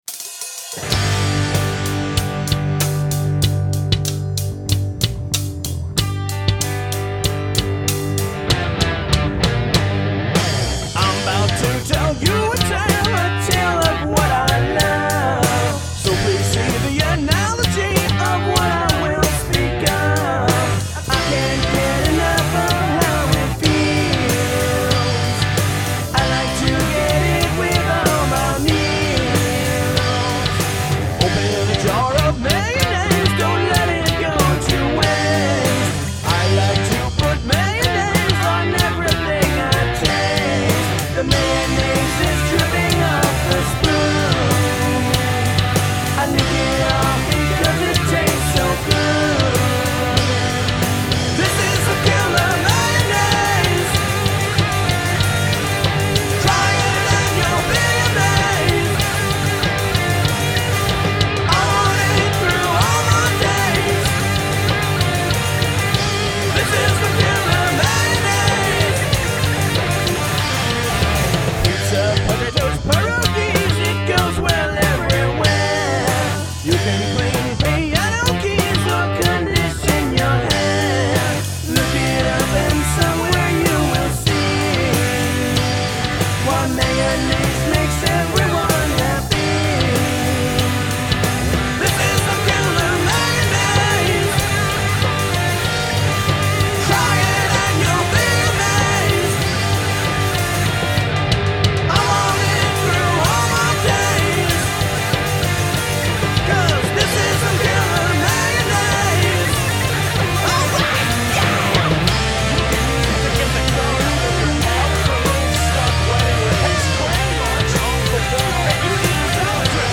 I'm playing hard rock still but it's a style that's a little out of my box.
I think the mix sounds alright. I know the tracks could probably use a re-record but I'm looking to get an overall opinion.